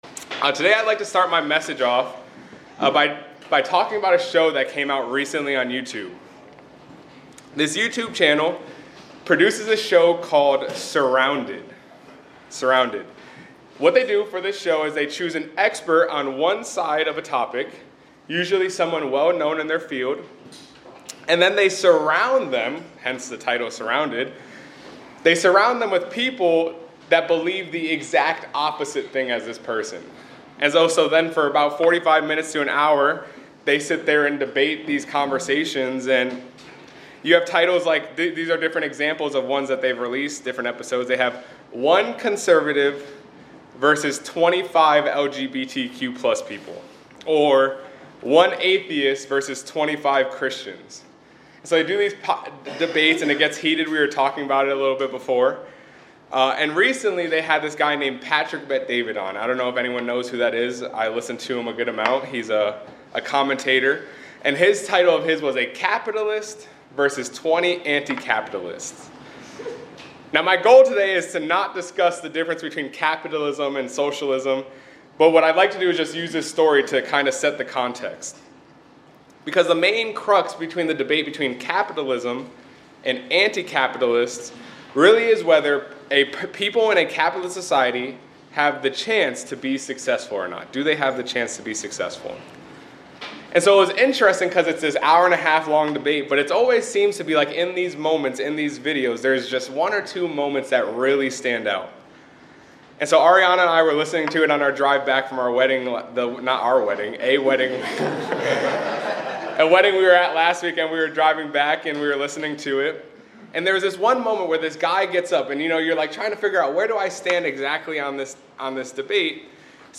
This sermonette reflects on the concept of victimhood in today’s troubled world, using a recent YouTube debate as a starting point to explore attitudes toward hardship and opportunity. It emphasizes the Christian perspective of hope through faith and the importance of active perseverance despite societal challenges.